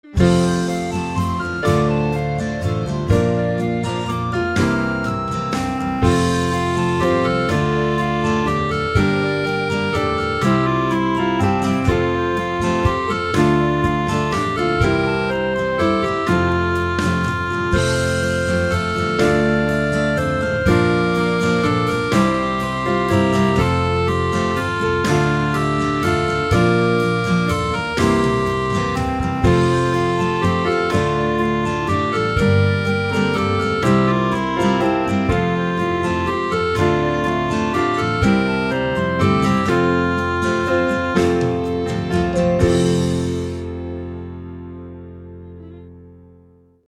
Gospel Acclamation
My backing is just refrain/verse/refrain, however.